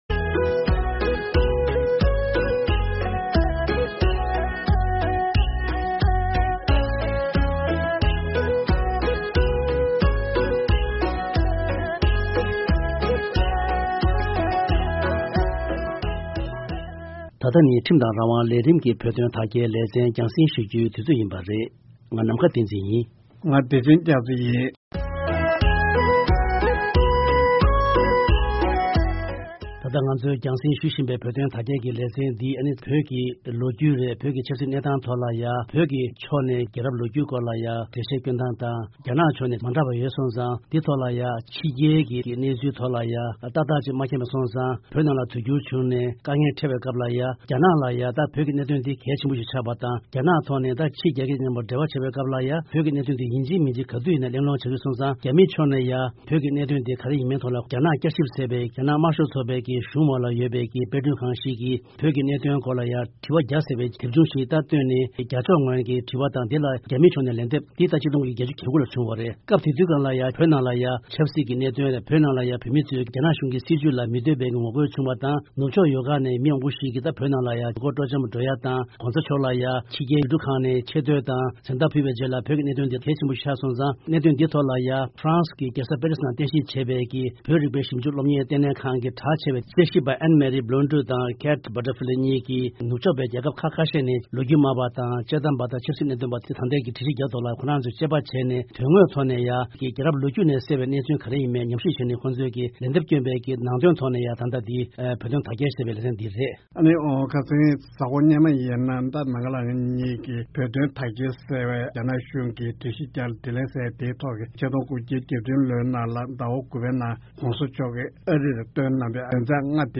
གླེང་མོལ་བྱས་ཡོད།